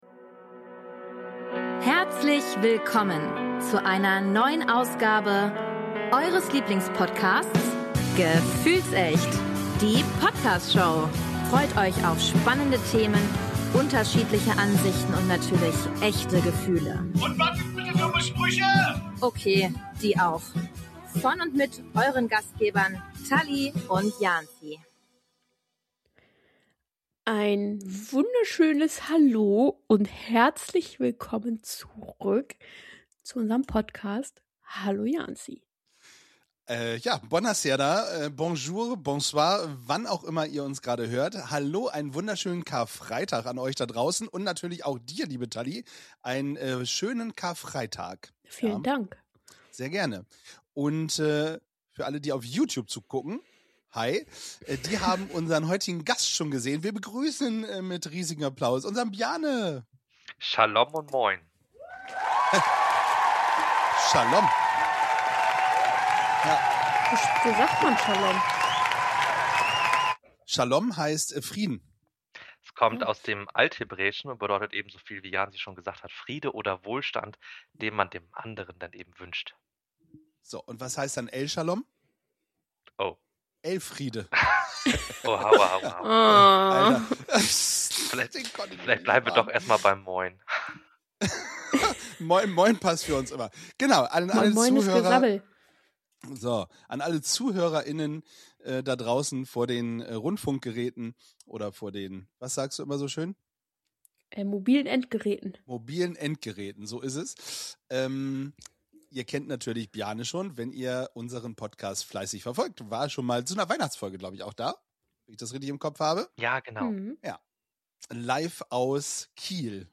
Und woher kommen eigentlich typische Osterbräuche wie Eier und der Osterhase? Eine Folge zwischen Wissen, Reflexion und ehrlichen Gesprächen – passend zur Osterzeit.